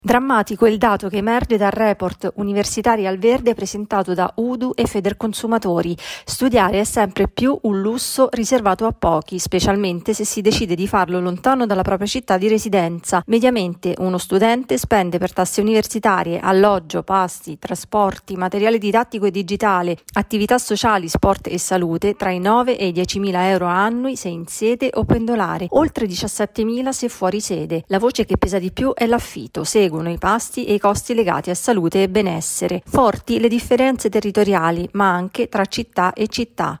Universitari al verde, presentato il Report sul costo degli studi all’università. Il servizio